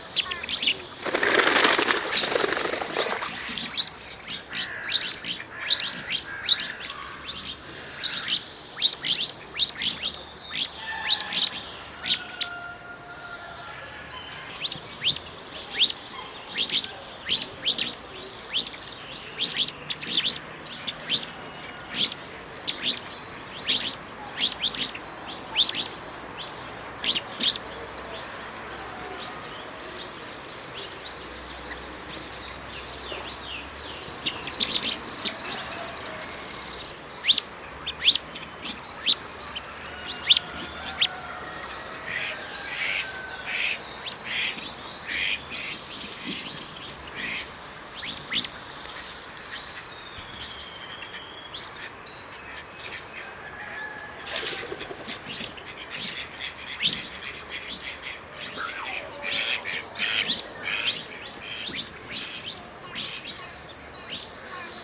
Illustration canard mandarin
canard-mandarin.mp3